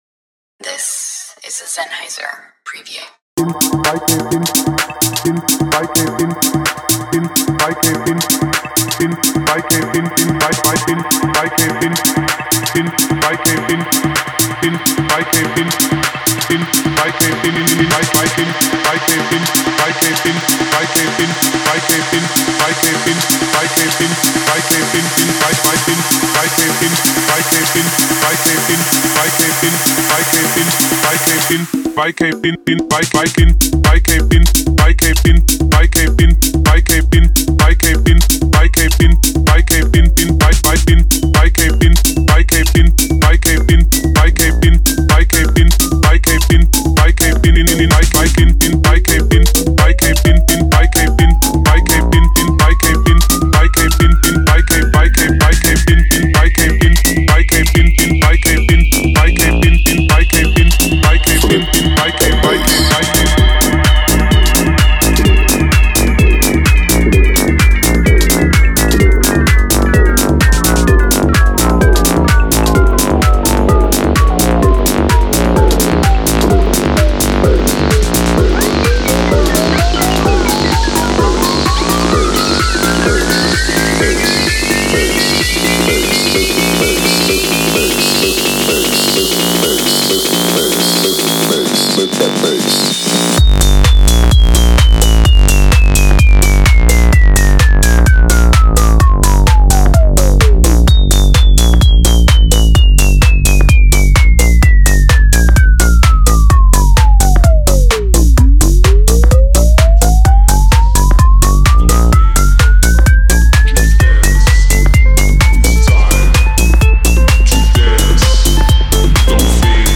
Genre:Tech House
完璧なハットのシャッフル、安定したベースラインの推進力、切れのあるボーカルフックのリズム。
このサンプルパックには、タイトでドライ、意図的に作られたドラムが収録されています。
ベースラインは意図的に転がり、低音の重みを持たせており、最も混み合ったシステムでも際立ちます。
ワンショットはタイトでパンチがあり、そのままミックス可能です。
キックは適切なトランジェントの歯切れを持ち、パーカッションは人間的な質感を意識しています。
デモサウンドはコチラ↓